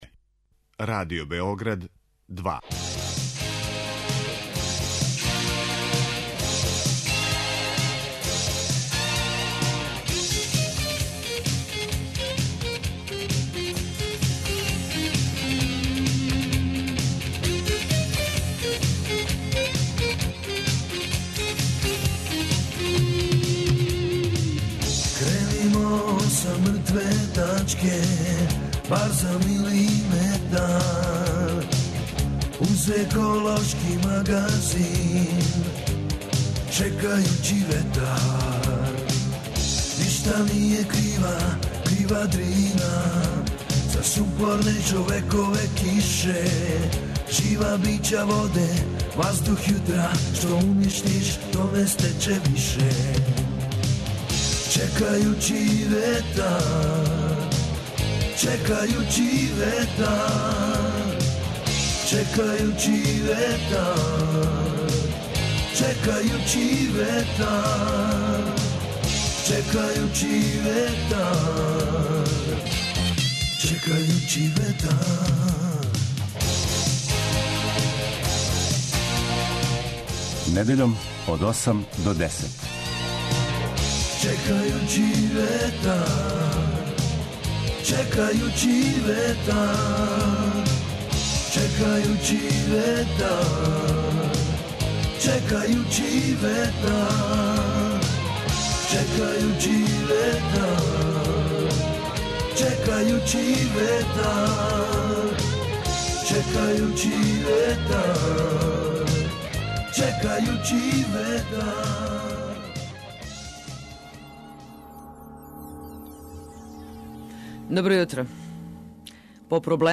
Еколошки магазин који се бави односом човека и животне средине, човека и природе.